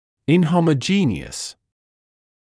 [ɪnˌhɔmə’ʤiːnɪəs][инˌхомэ’джиːниэс]негомогенный, неоднородный; неравномерный